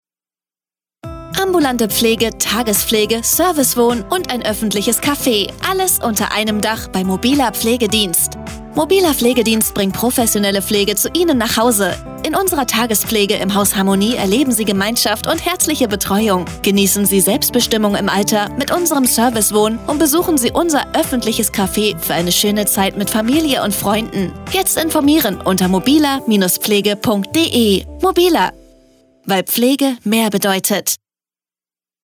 Radiospot.mp3